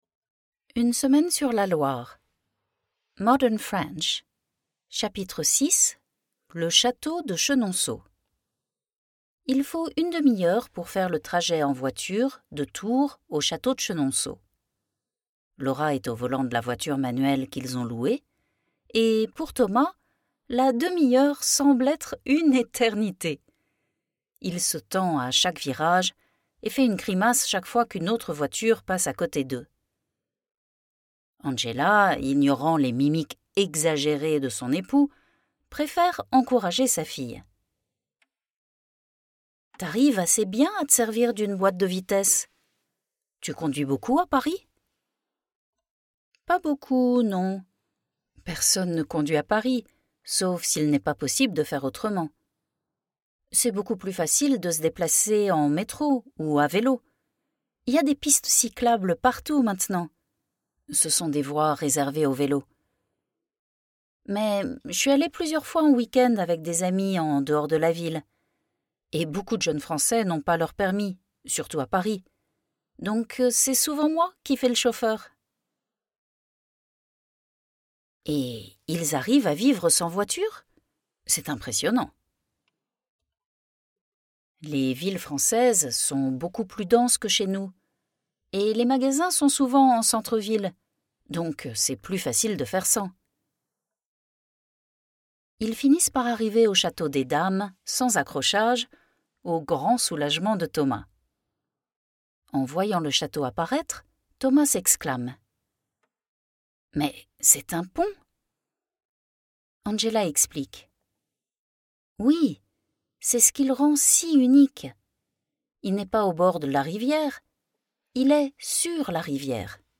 • A modern street version as natives would actually speak in Paris so that you can start preparing your ears
8 Hours 22 Mins of story chapters + Q&A exercises – all professionally recorded by a Parisian native + 186-page transcript with English translation.
Chapter 6 – Modern French Recording